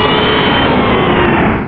Cri de Kyogre dans Pokémon Rubis et Saphir.